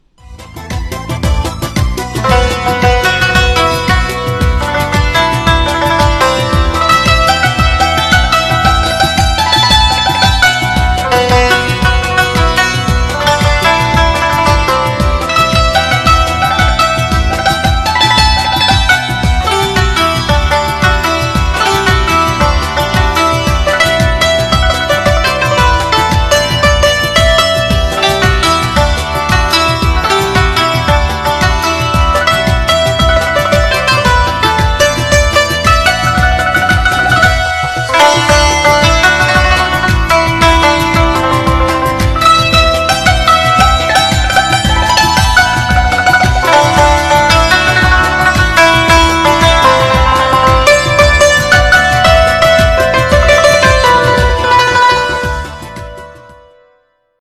نسخه بی کلام سنتور